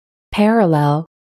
parallel a. 并行 [ˈpærəlel]